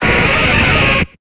Cri de Crustabri dans Pokémon Épée et Bouclier.